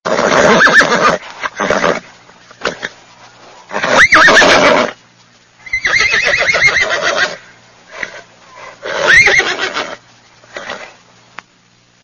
دانلود آهنگ اسب از افکت صوتی انسان و موجودات زنده
جلوه های صوتی
دانلود صدای اسب از ساعد نیوز با لینک مستقیم و کیفیت بالا